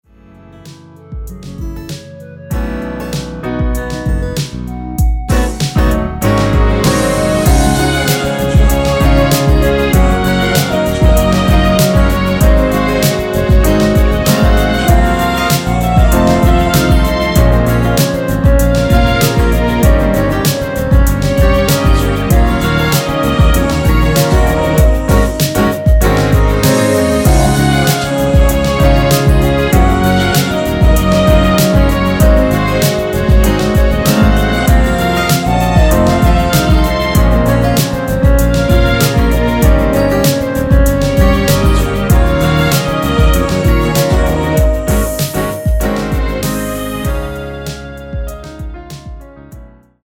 원키에서(-6)내린 멜로디와 코러스 포함된 MR입니다.(미리듣기 확인)
앞부분30초, 뒷부분30초씩 편집해서 올려 드리고 있습니다.
중간에 음이 끈어지고 다시 나오는 이유는